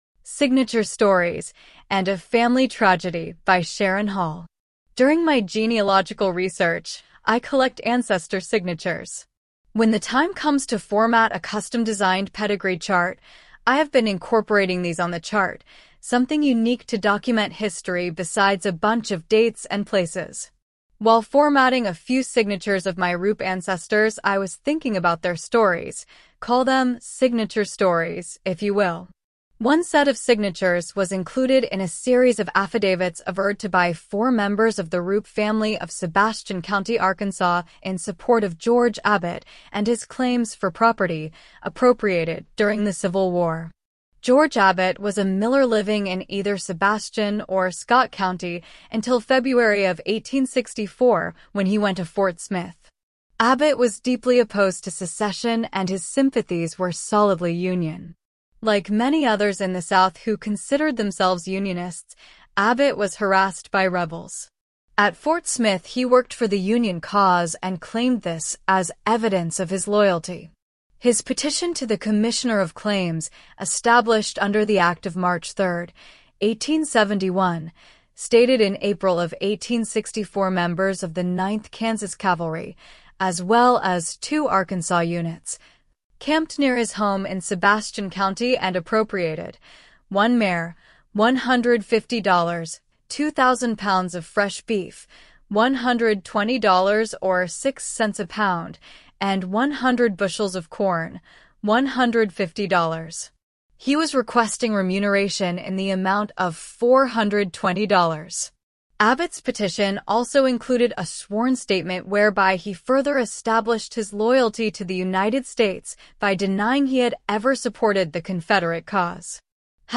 A few days ago I posted a sample of AI Text to Speech technology, and I’m slowly learning some (but not all) of the ins-and-outs.  Today’s post features a short article written for the Arkansas issue (published recently).